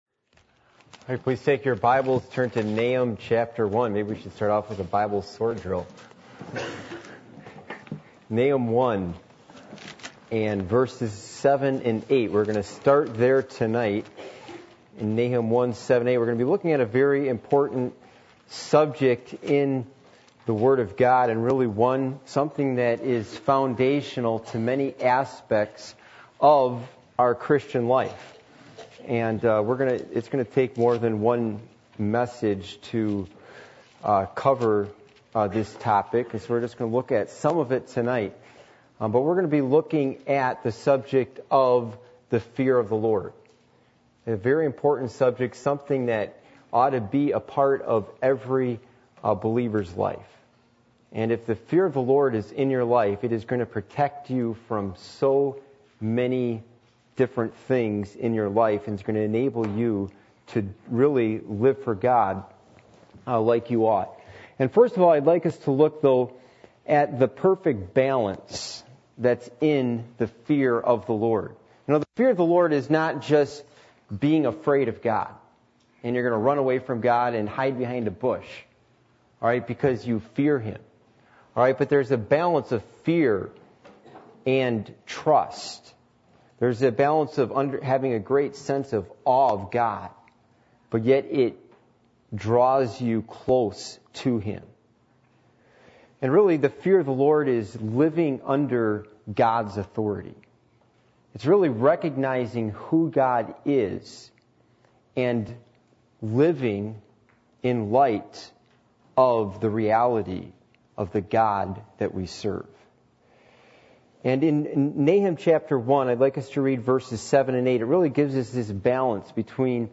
Passage: Nahum 1:7-8 Service Type: Midweek Meeting